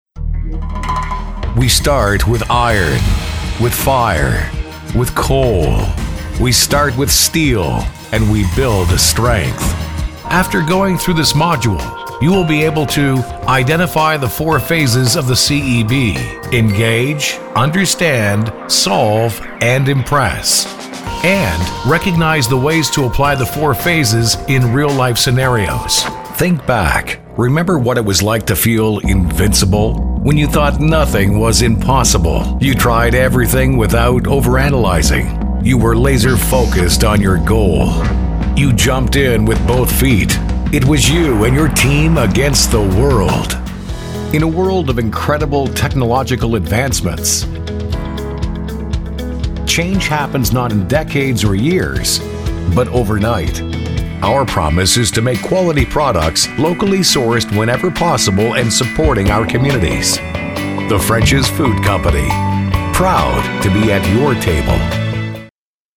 Professional male voice talent
Corporate Demo